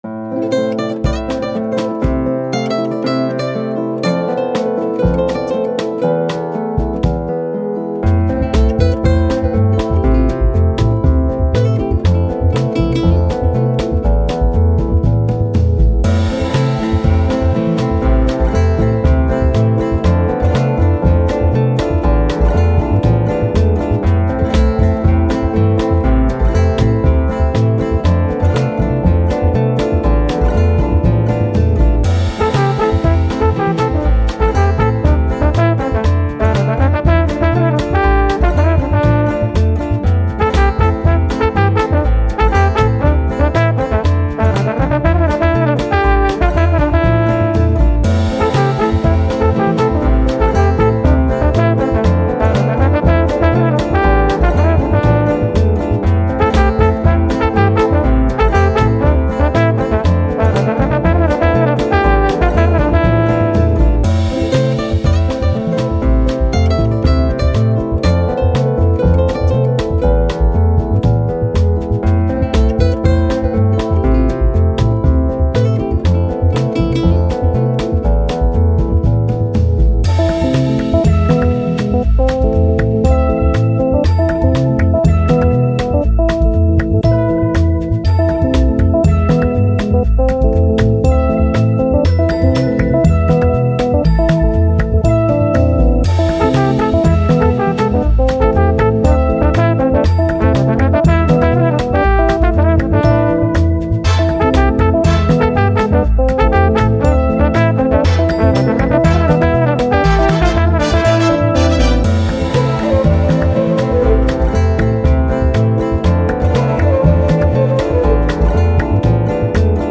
Latin, Spanish, Thoughtful, Quirky, Upbeat